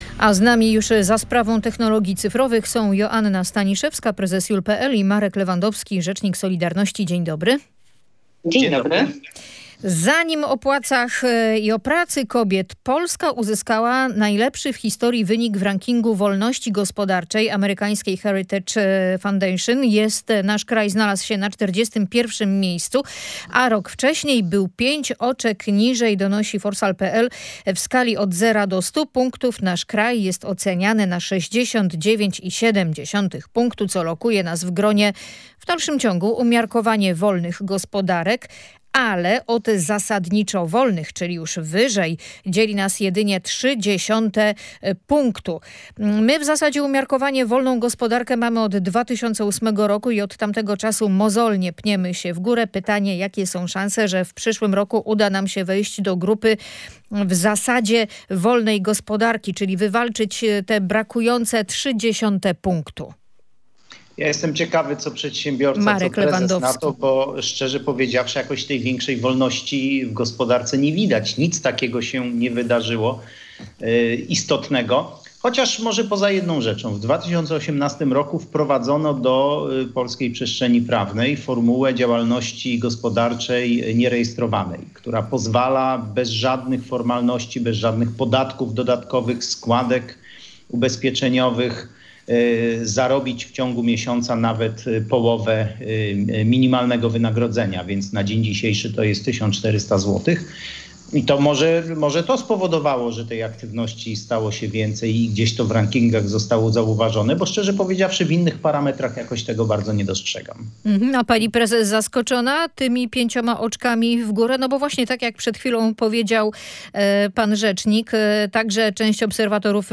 Czy te różnice widać gołym okiem na polskim rynku pracy? O tym rozmawialiśmy w audycji „Ludzie i Pieniądze”.